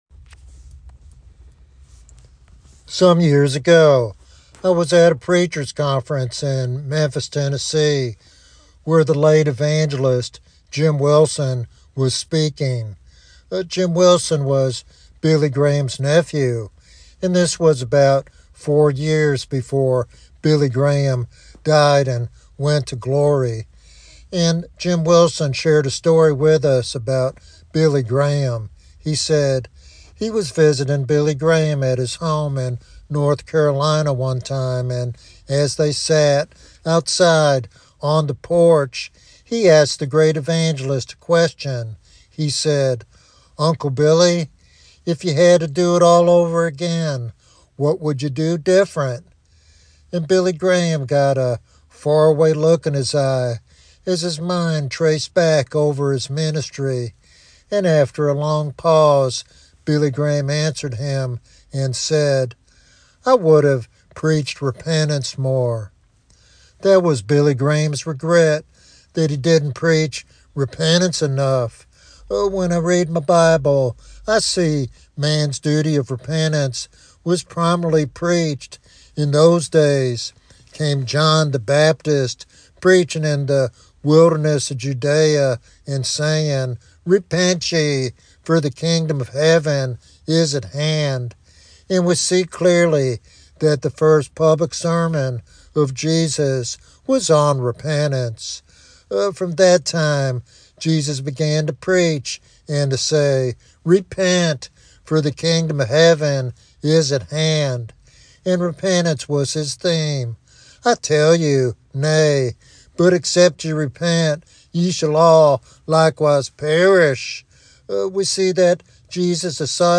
The message challenges listeners to consider their own spiritual legacy and the eternal importance of repentance.